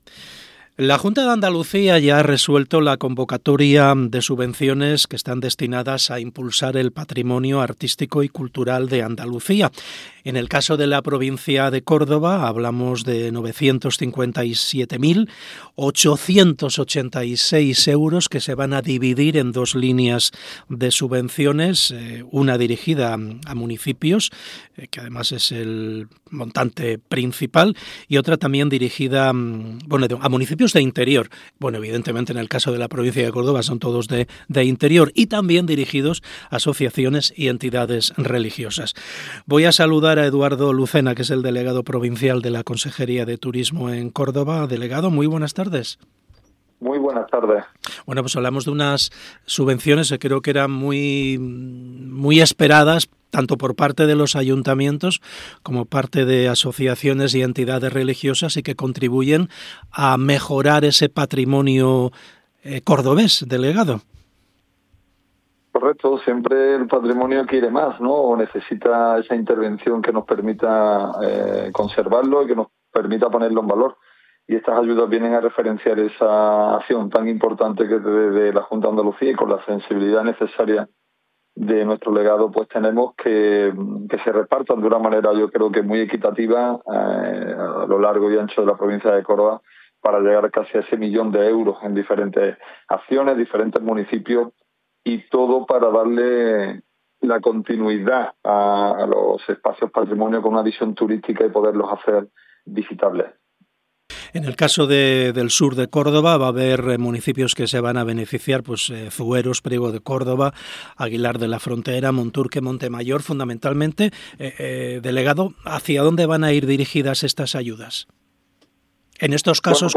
Entrevista Eduardo Lucena. Subvenciones patrimonio artístico - Andalucía Centro
Hablamos con Eduardo Lucena, delegado territorial de la Consejería de Turismo en Cordoba.